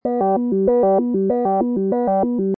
Descarga de Sonidos mp3 Gratis: alarma 17.